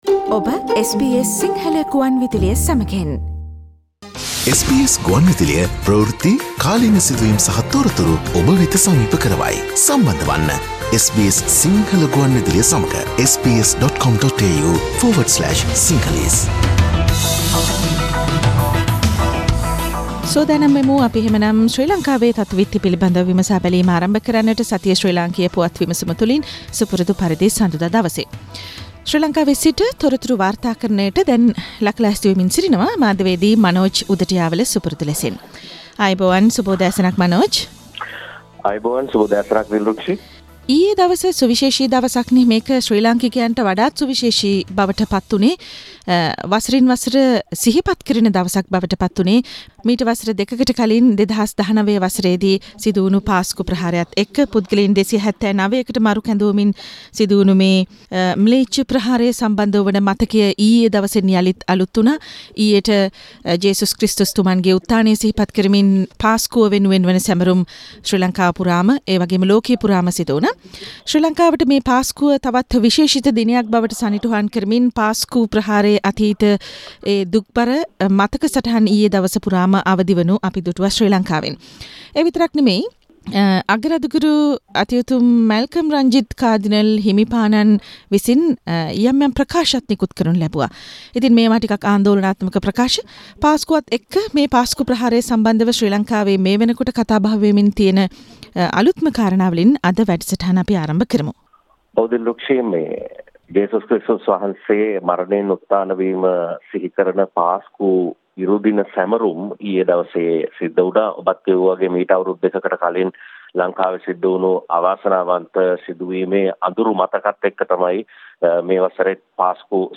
SBS Sinhala radio brings you a comprehensive wrap up of the highlighted news from Sri Lanka